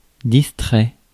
Ääntäminen
France: IPA: [dis.tʁɛ]